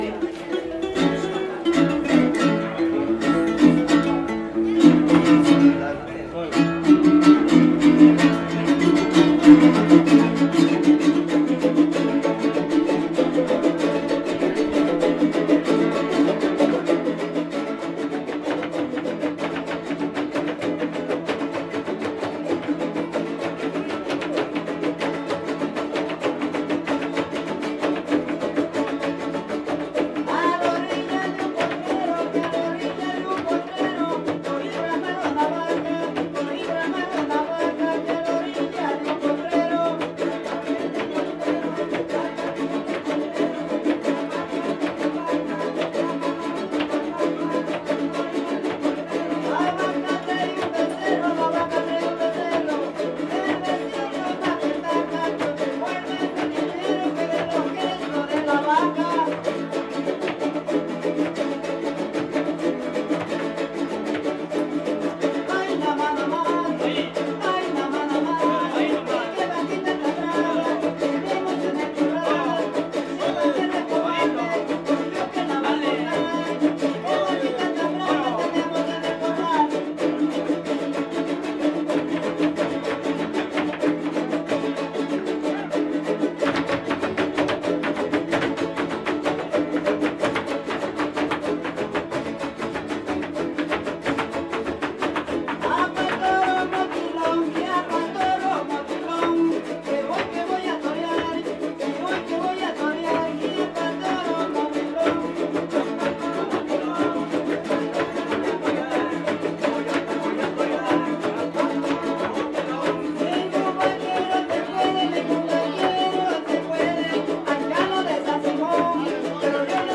Tres Zapotes, Veracruz
Copla Son jarocho Canción tradicional
Fiesta Patronal de San Antonio de Padua